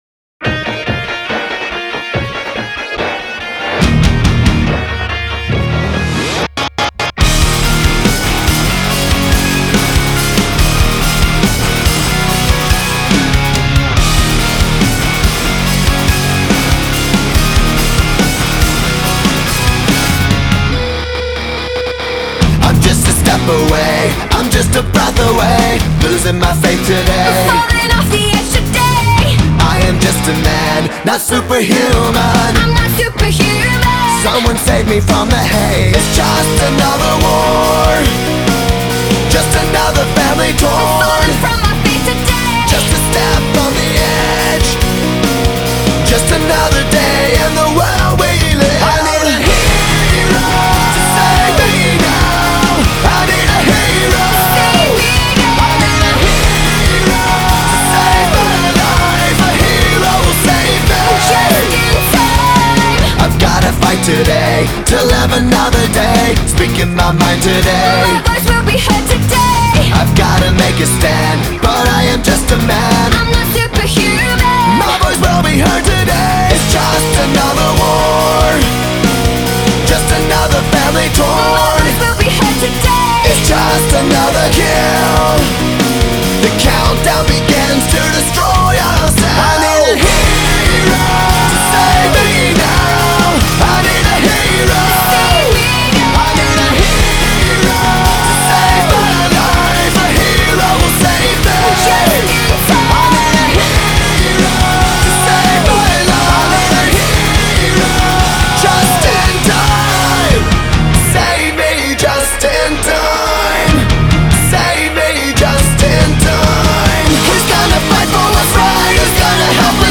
Главная » Онлайн музыка » Rock (6)